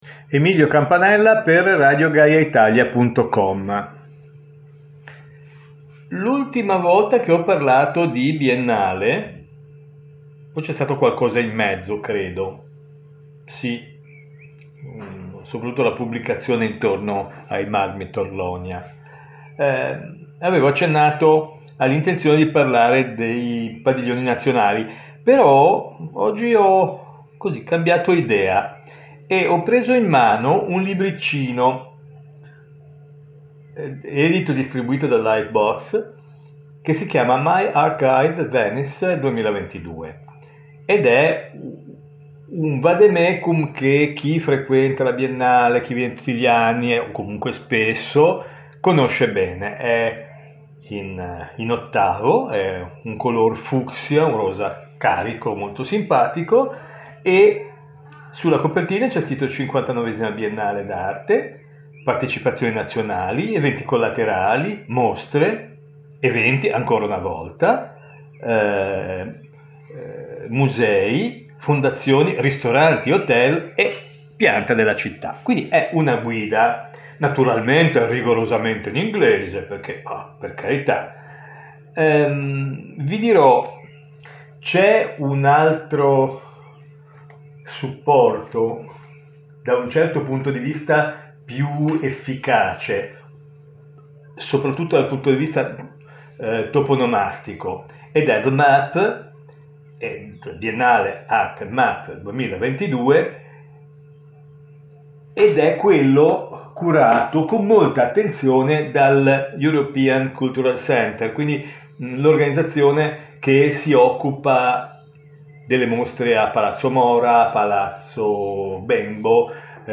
Una guida d’Arte parlata